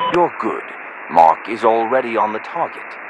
Radio-jtacSmokeAlreadyOut4.ogg